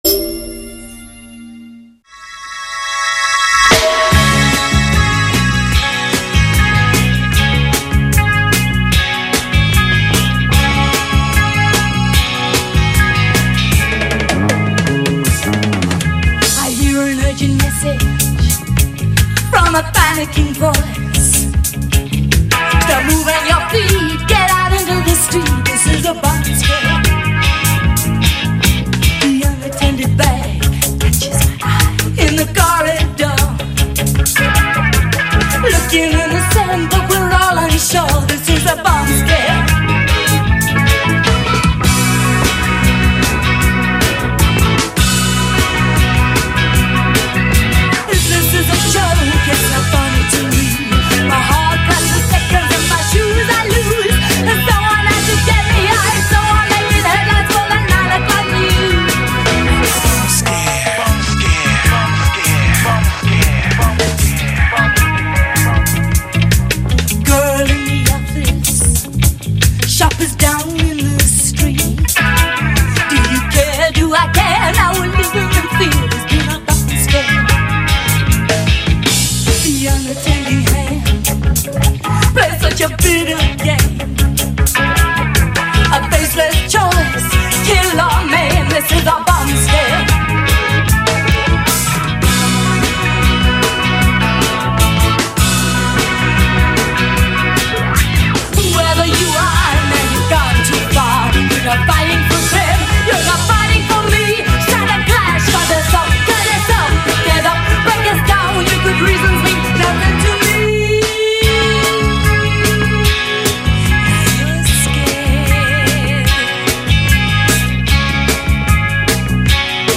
Vos esgourdes seront abreuvées de 50’s Rockabilly